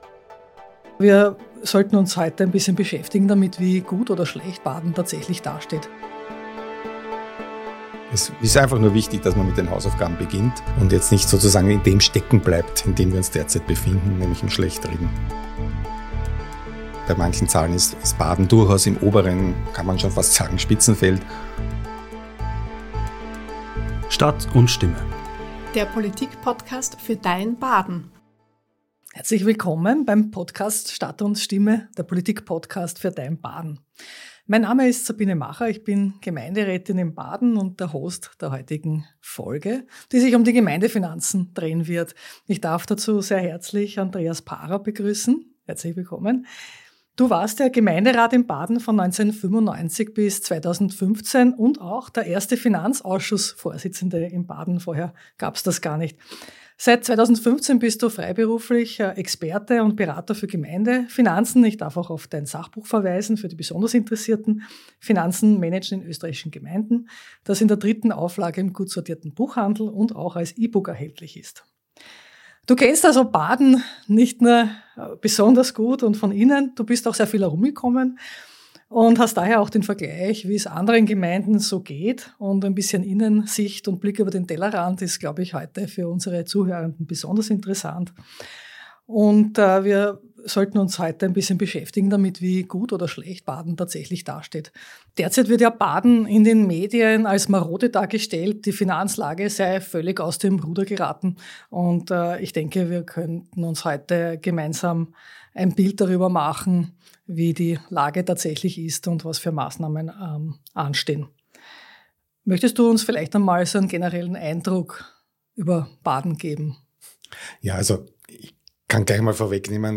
Gemeinderätin Sabine Macha spricht mit dem Experten für Gemeindefinanzen Andreas Parrer, der nicht nur langjähriger Gemeinderat in Baden war, sondern heute Gemeinden in ganz Österreich berät. Gemeinsam erklären sie, wie Gemeindefinanzen wirklich funktionieren: Was bedeuten Schuldenstand, Vermögenswerte und Abschreibungen?